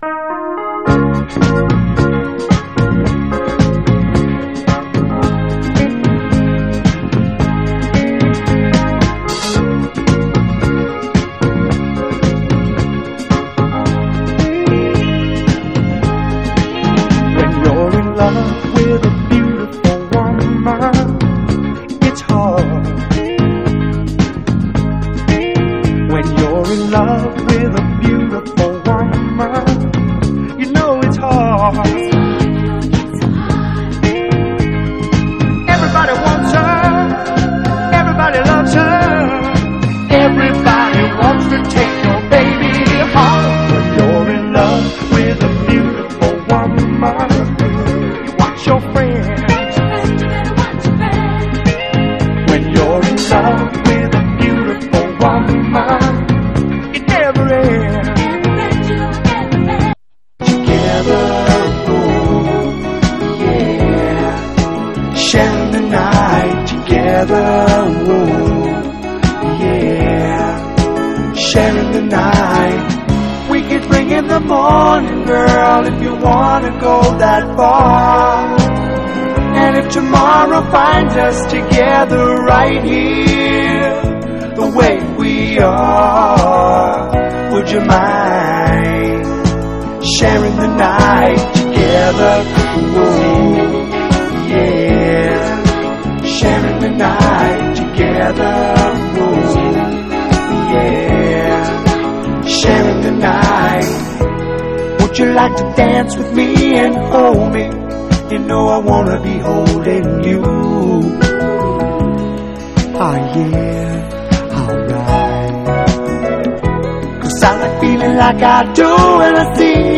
ROCK / PUNK / 80'S～ / JAPANESE PUNK